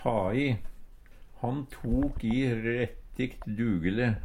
ta i - Numedalsmål (en-US)